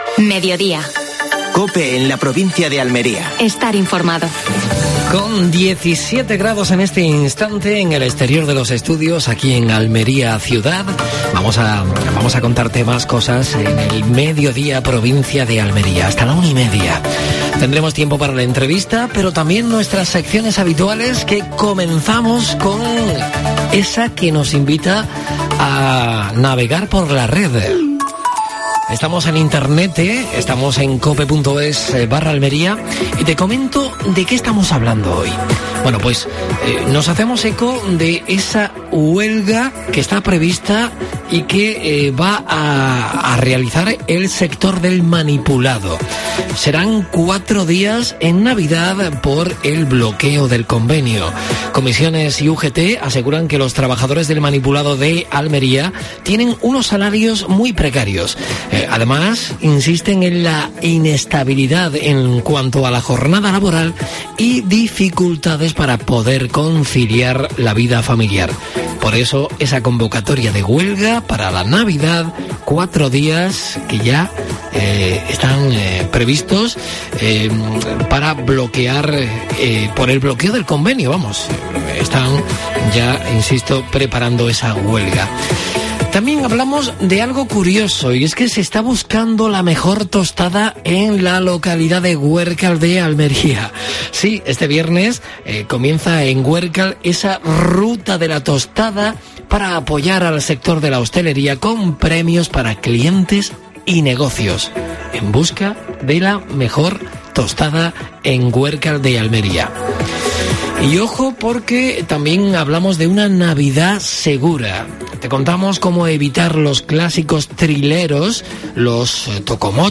AUDIO: Actualidad en Almería. Última hora deportiva. Entrevista a Carmen Navarro (diputada provincial).